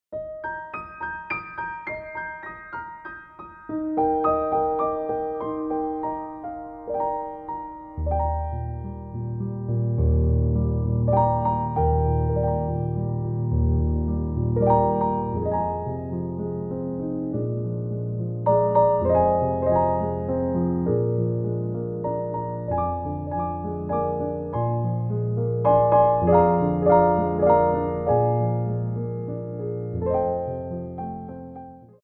33 Tracks for Ballet Class.
Warm Up
3/4 (16x8) + (1x8)